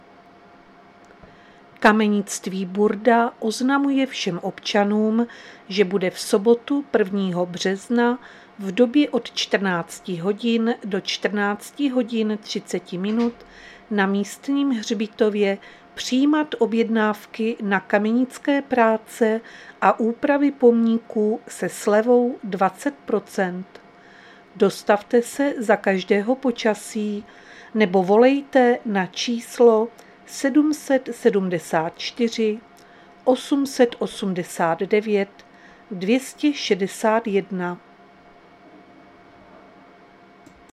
Záznam hlášení místního rozhlasu 26.2.2025